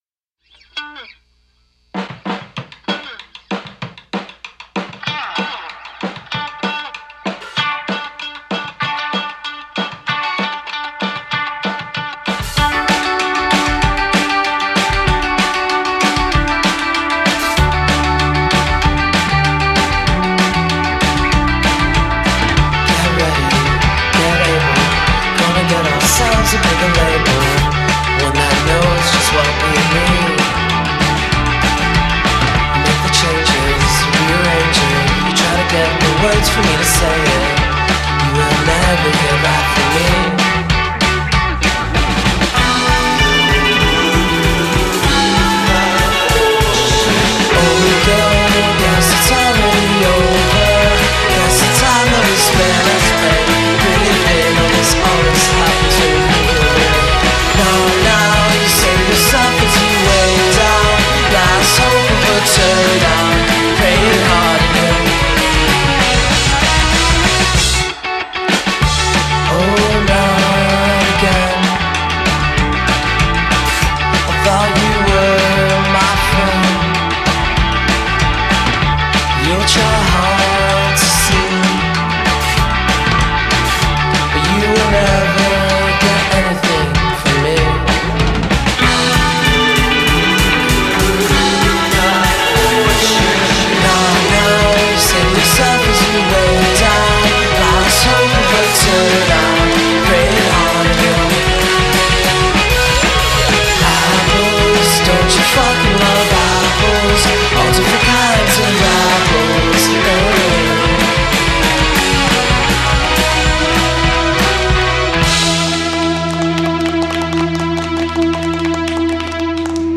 indiepop
energica
piena di urgenza ma anche ipermelodica